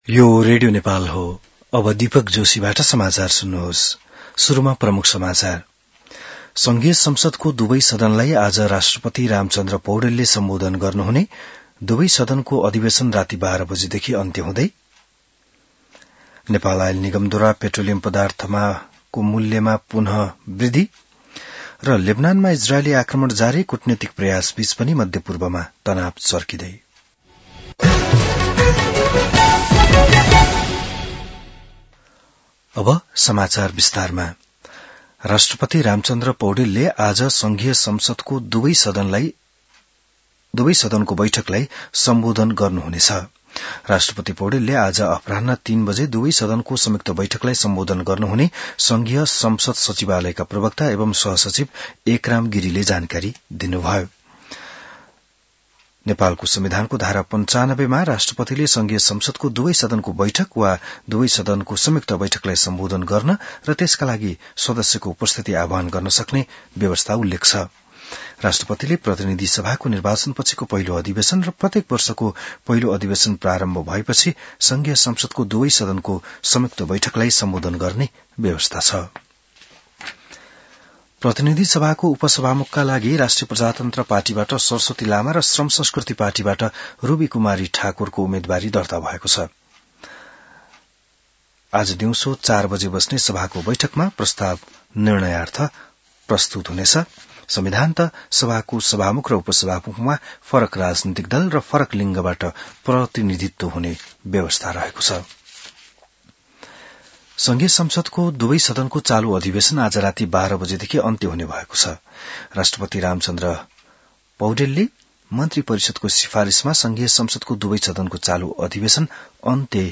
An online outlet of Nepal's national radio broadcaster
बिहान ९ बजेको नेपाली समाचार : २७ चैत , २०८२